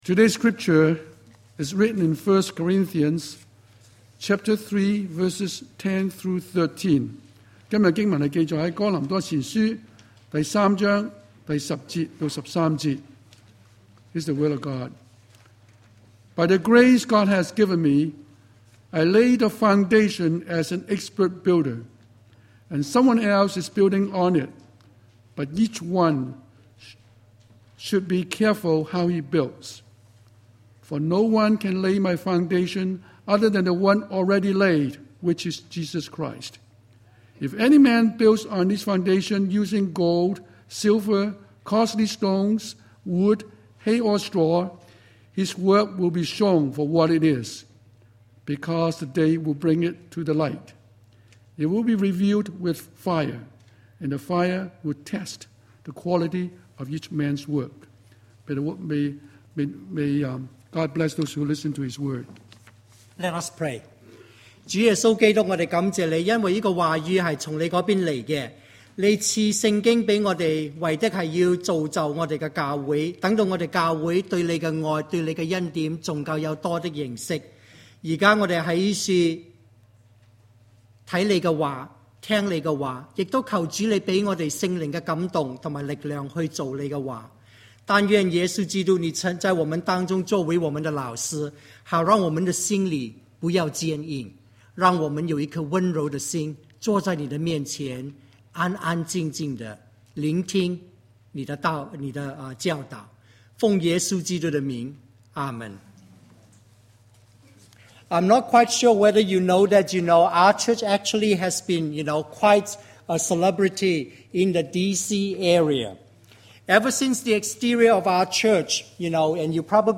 The Lord’s Servants: January 3, 2010 Sermon